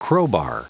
Prononciation du mot crowbar en anglais (fichier audio)
Prononciation du mot : crowbar